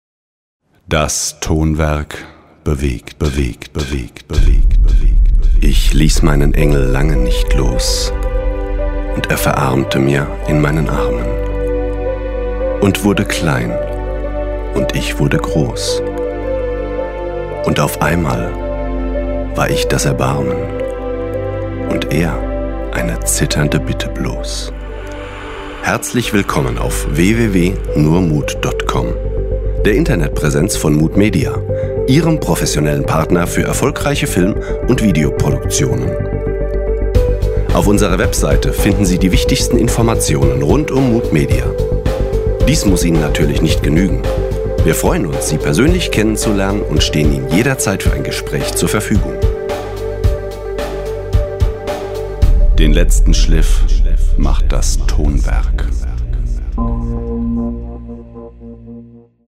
Sonore Stimme, sehr geeignet auch für Meditation und Hypnose
Kein Dialekt
Sprechprobe: Werbung (Muttersprache):